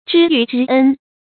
知遇之恩 zhī yù zhī ēn 成语解释 给予赏识或重用的恩情。